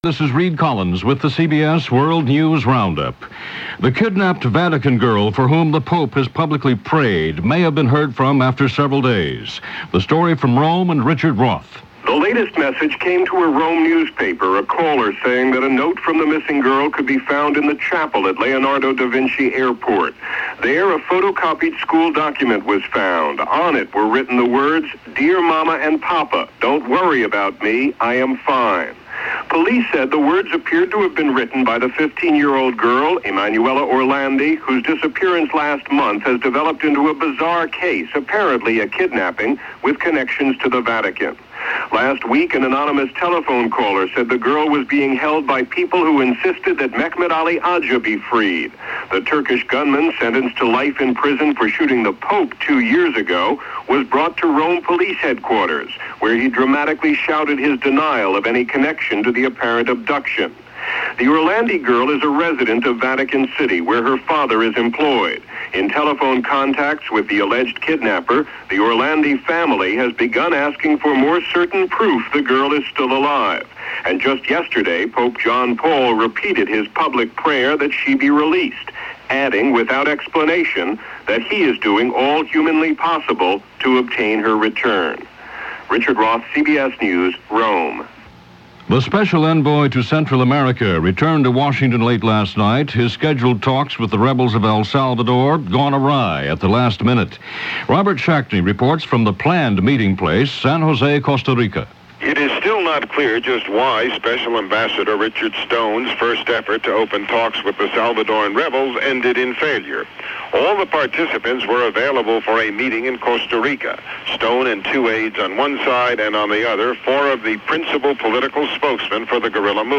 July 11, 1983 – CBS World News Roundup + 9:00 am network news